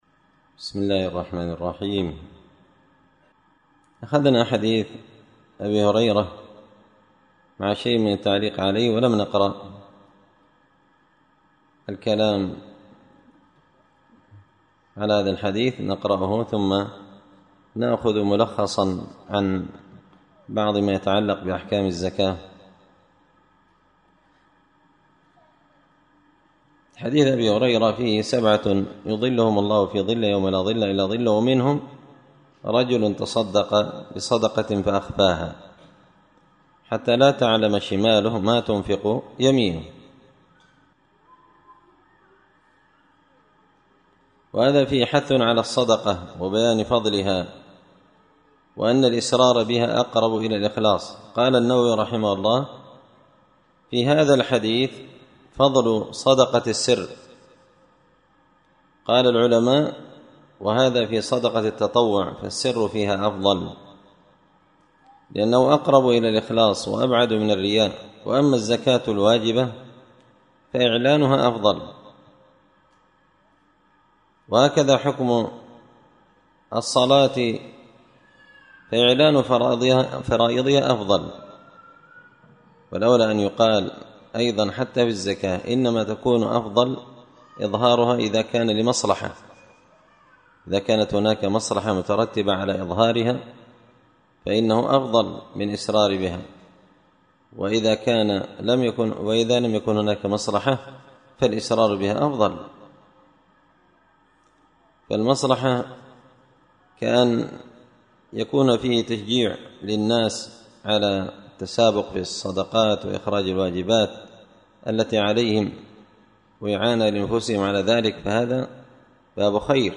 منتقى الأفنان في فقه الصوم وأعمال رمضان الدرس الثاتي و العشرون